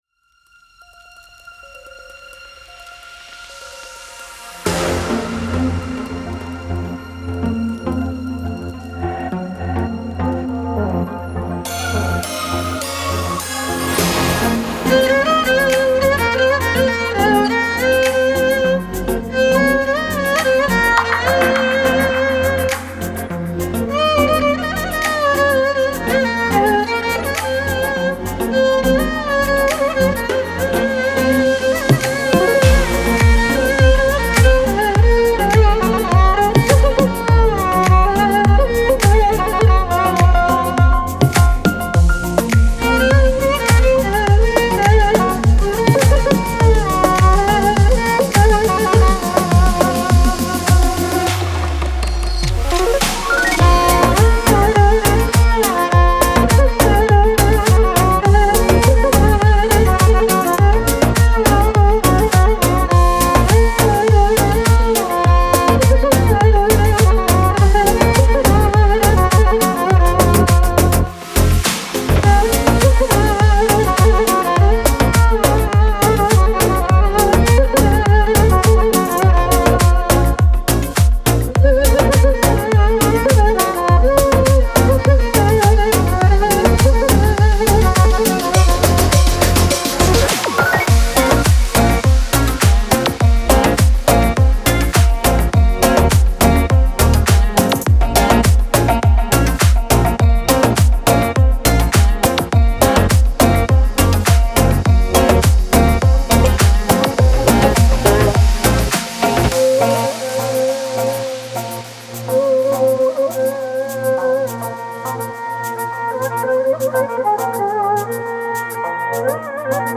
سبک ریتمیک آرام
موسیقی بی کلام اورینتال موسیقی بی کلام کمانچه